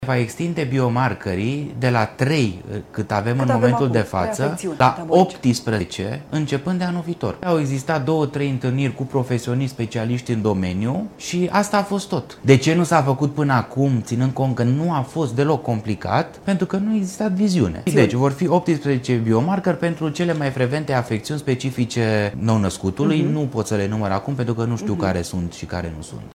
Vești bune pentru testarea nou-născuților: de anul viitor, bebelușii vor putea fi analizați în maternități pentru 18 biomarkeri, în loc de trei, cum este în prezent, a anunțat ministrul Sănătății, la MedikaTV.
Ministrul Sănătății, Alexandru Rogobete: „Deci vor fi 18 biomarkeri pentru cele mai frecvente afecțiuni specifice nou-născutului”